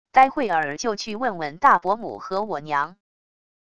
待会儿就去问问大伯母和我娘wav音频生成系统WAV Audio Player